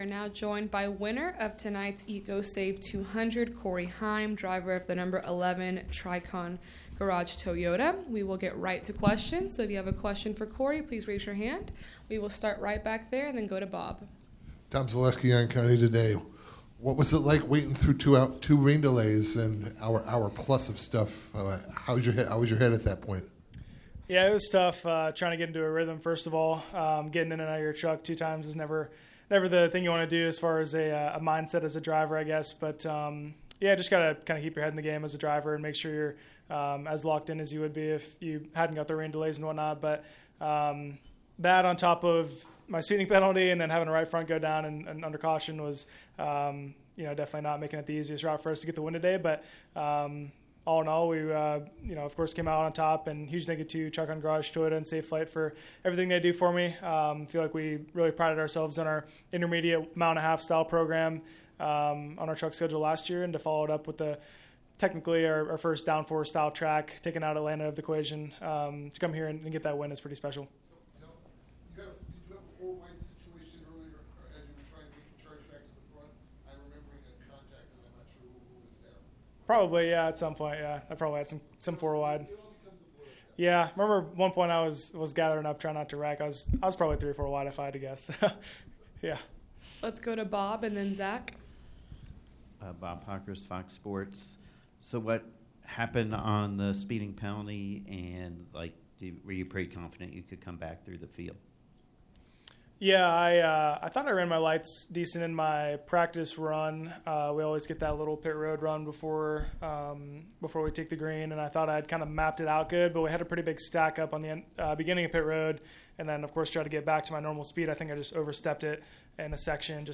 Interview:
NCTS Race Winner Corey Heim (No. 11 TRICON Garage Toyota) –